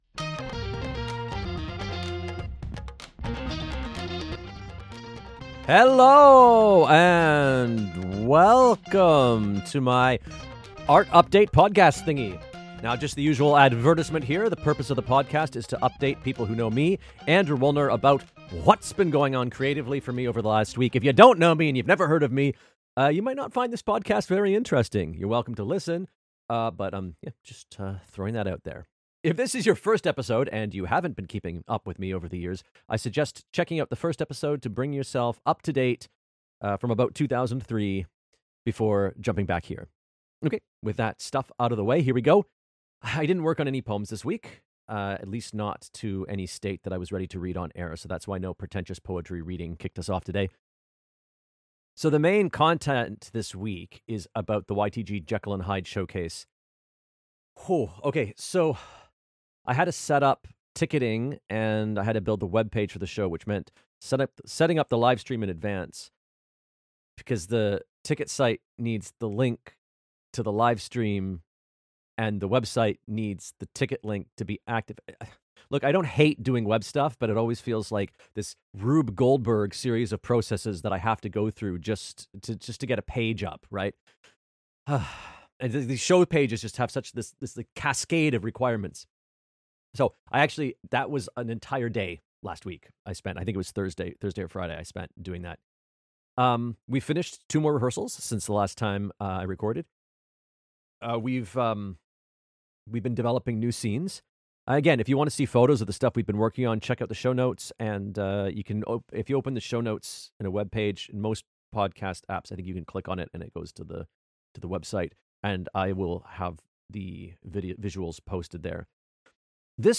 I didn't make my own deadline on this, mostly because I had issues with my microphone in the 20 minutes I had to record this on Wednesday and I wasn't able to get back to recording until Thursday afternoon.